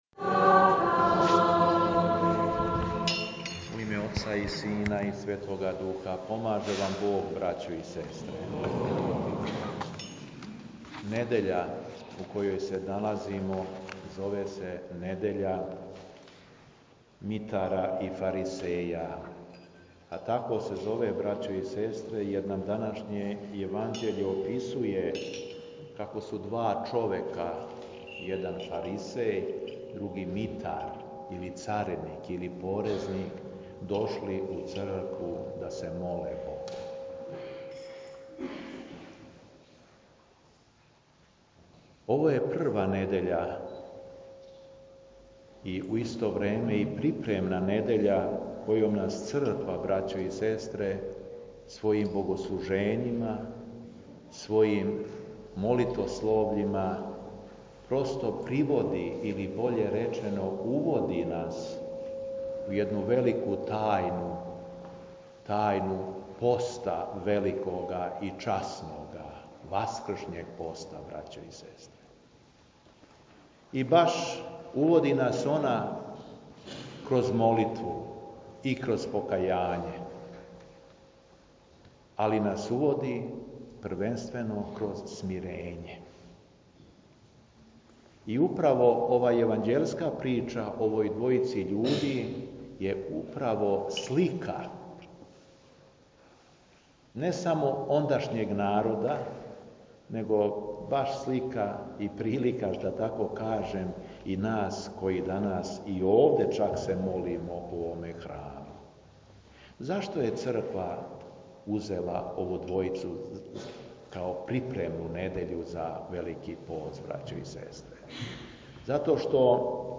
У недељу, 21. фебруара 2016. године, када наша Црква прославља Светог Теодора Стратилата, Светог пророка Захарију и Светог Саву II, архиепископа српског, Његово Преосвештенство Епископ шумадијски Г. Јован служио је Свету Архијерејску Литургију у храму Успења Пресвете Богородице у Младеновцу.
Беседа Епископа шумадијског Г. Јована